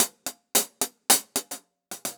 Index of /musicradar/ultimate-hihat-samples/110bpm
UHH_AcoustiHatC_110-03.wav